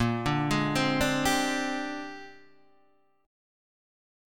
A#13 Chord